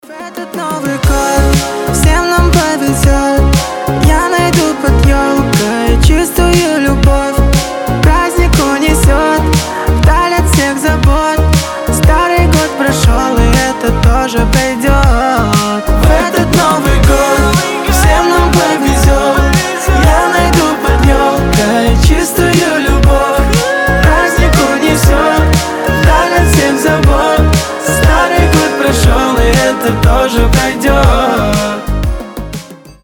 • Качество: 320, Stereo
мужской голос
добрые